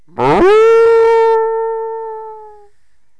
bull_die4.wav